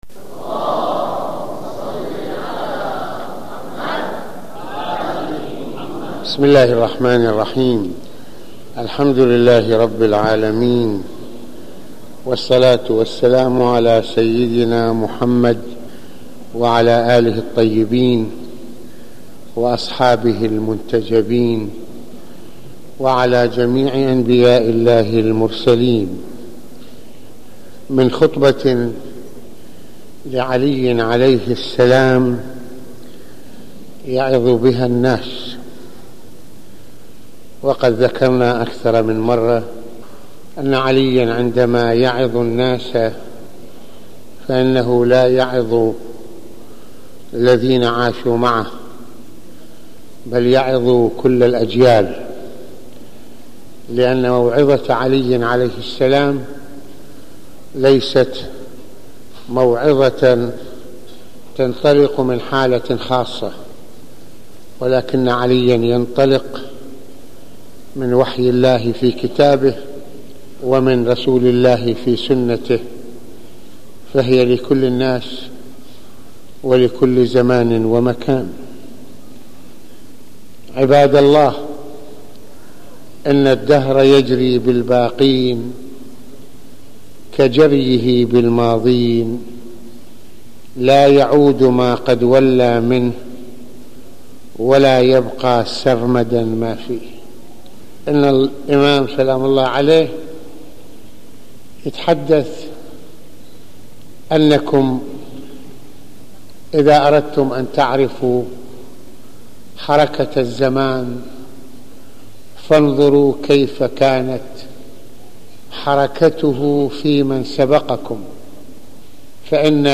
ملفات وروابط - المناسبة : موعظة ليلة الجمعة المكان : مسجد الإمامين الحسنين (ع) المدة : 31د | 12ث المواضيع : من خطبة لعلي (ع) يعظ بها الناس - ضرورة الاحساس بالزمن - لا يعود ما قد ولي من الدهر - لا يبقى